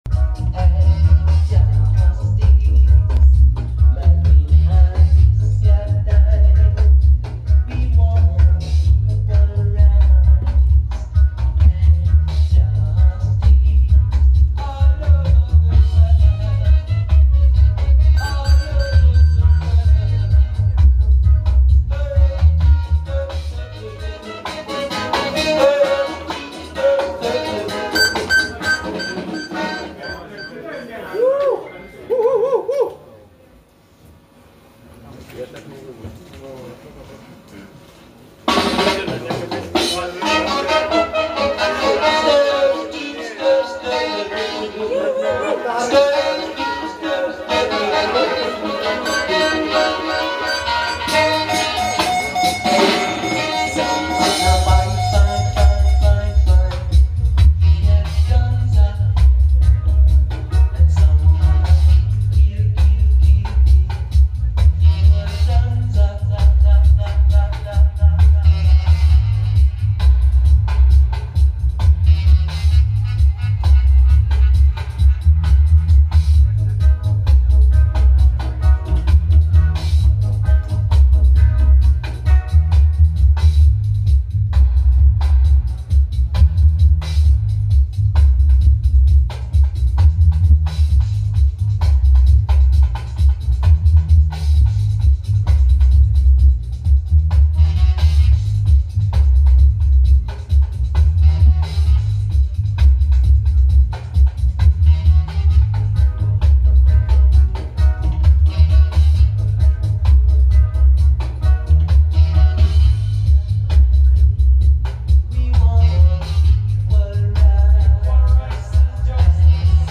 Raw recording